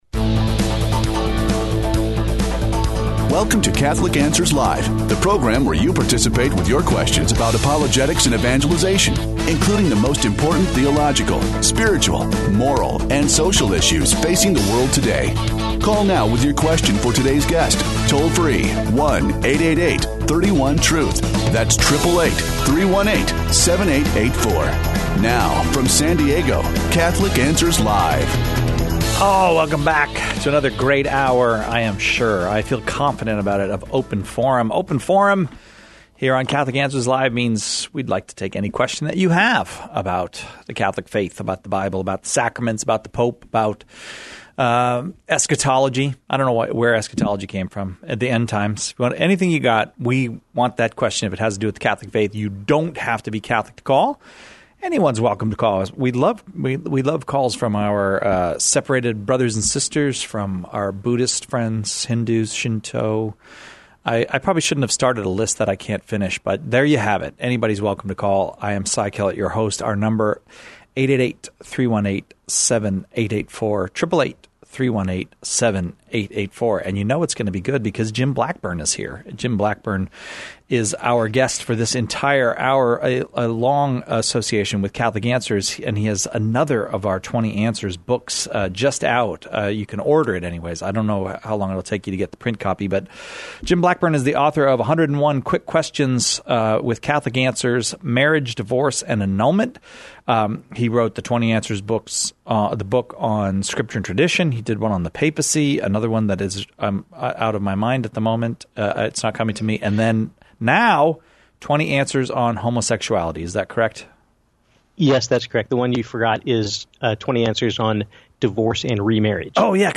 The callers choose the topics during Open Forum, with questions on every aspect of Catholic life and faith, the moral life, and even philosophical topics.